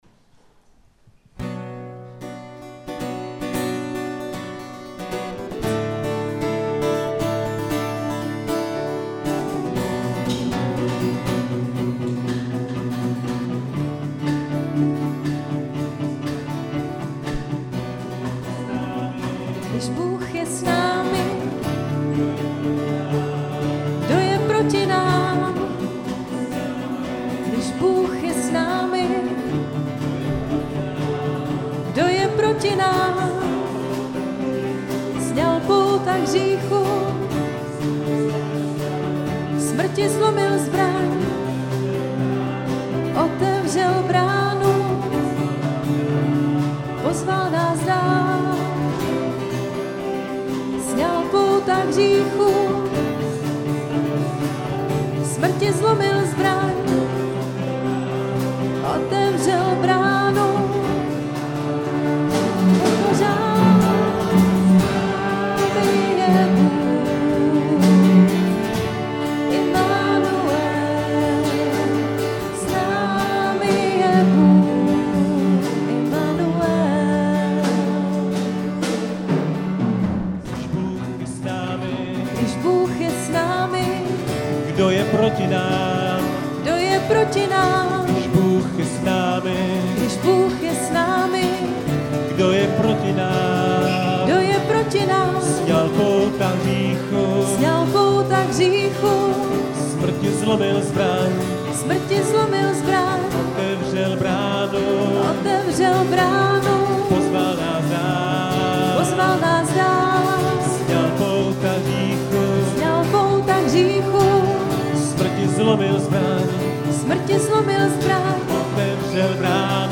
Chvály: 11.9.2016 – Když Bůh je s námi; Pojďme díky vzdávat; Náš Bůh
Událost: Bohoslužby Autor: Skupina CB Praha 2